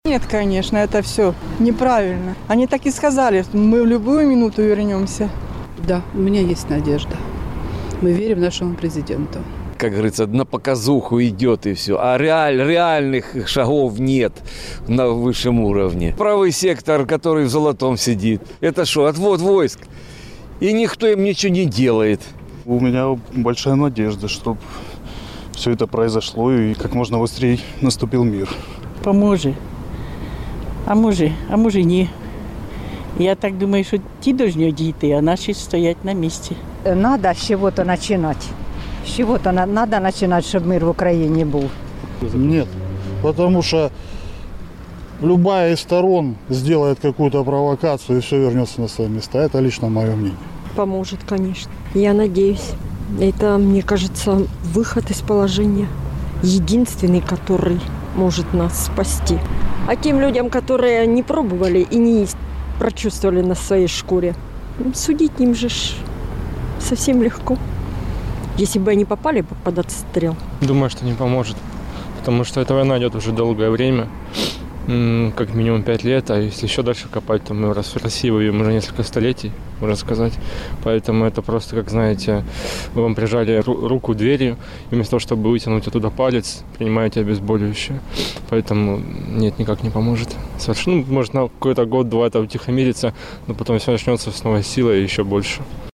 Радіо Донбас.Реалії запитує на вулицях Слов'янська про розведення військ. Чи допоможе воно закінчити війну?